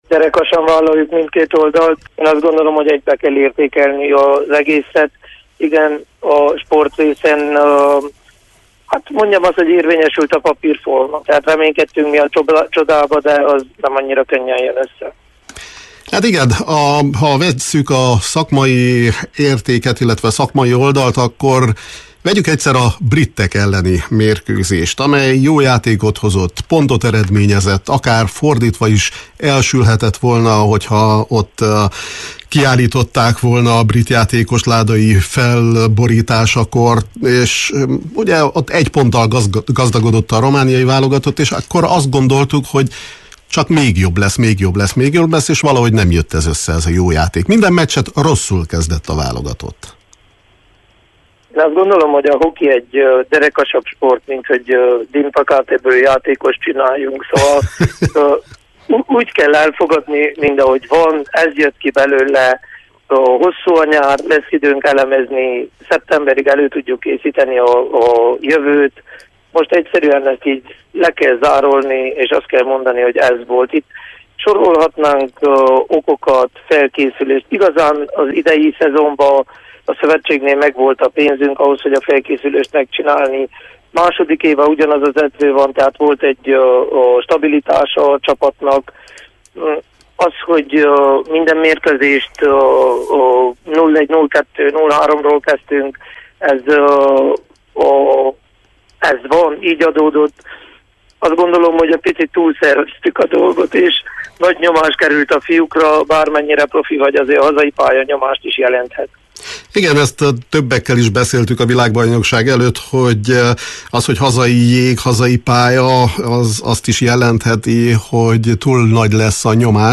beszélgetett a Kispad sportműsorunkban